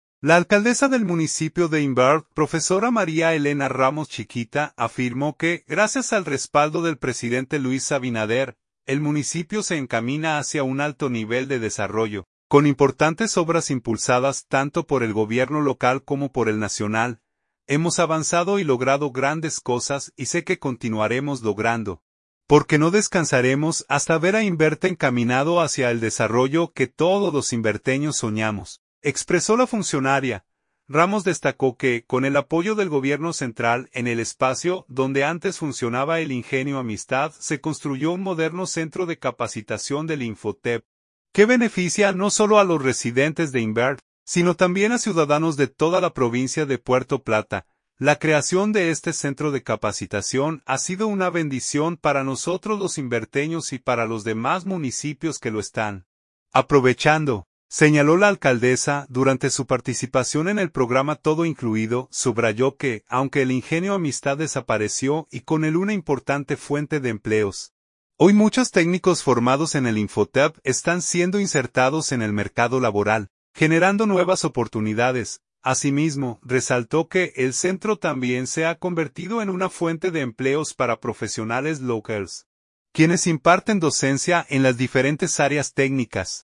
“La creación de este centro de capacitación ha sido una bendición para nosotros los imberteños y para los demás municipios que lo están aprovechando”, señaló la alcaldesa durante su participación en el programa ‘Todo Incluido’.